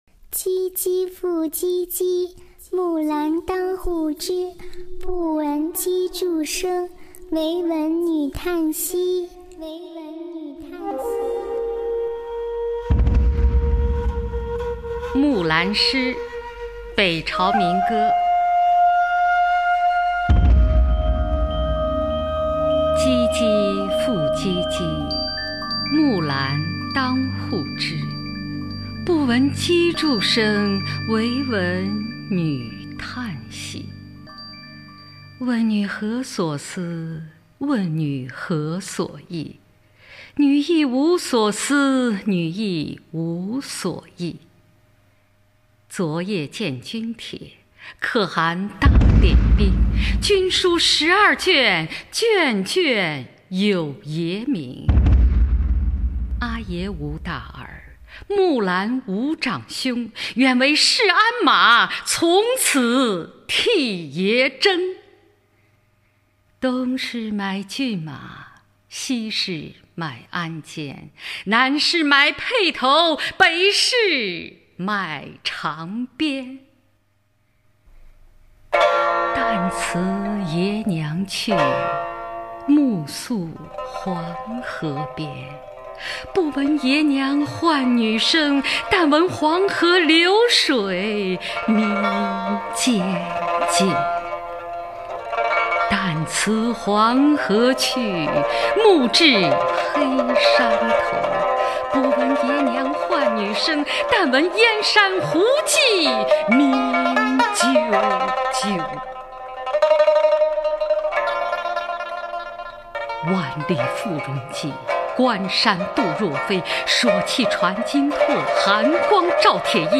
[魏晋诗词诵读]北朝民歌-木兰辞 古诗朗诵